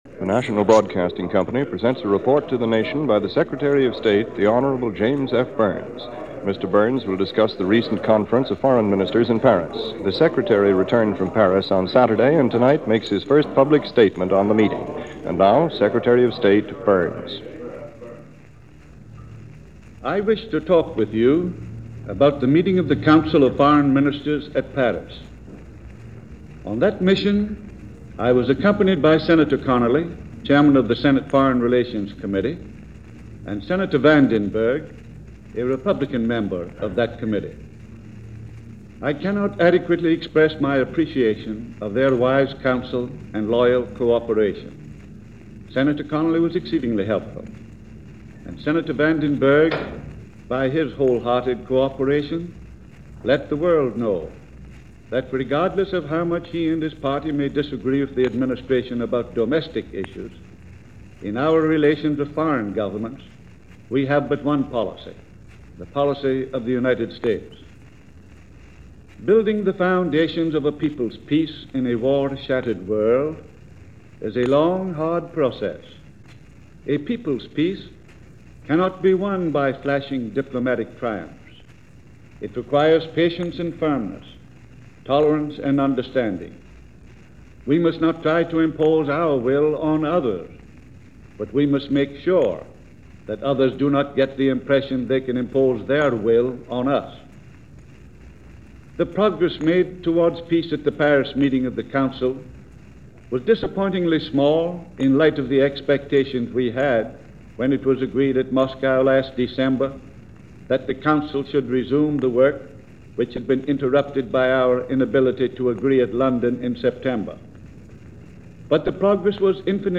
Giving a report card on the sessions, Secretary of State James Byrnes made a nationwide address and outlined what was accomplished during this session.